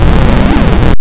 GARGSCREAM.WAV